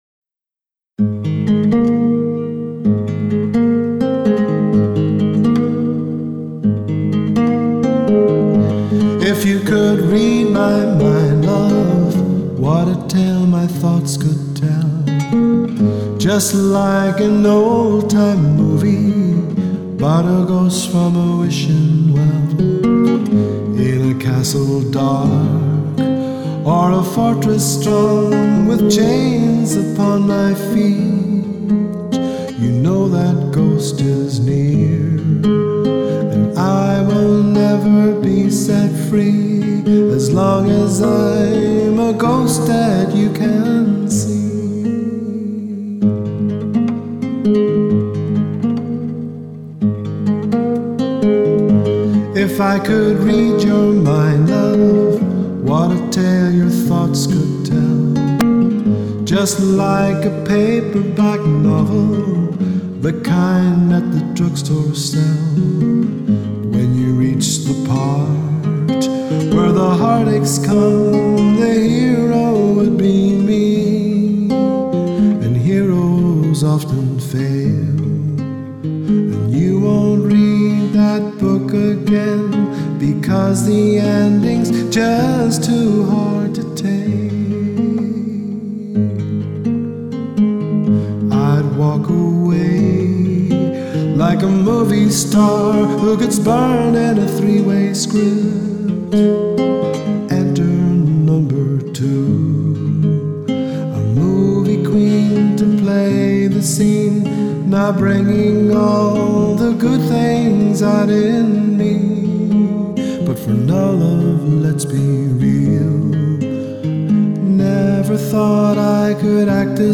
voice & guitar